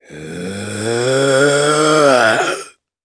Chase-Vox_Casting3_jp.wav